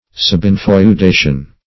Subinfeudation \Sub*in`feu*da"tion\, n. (Law)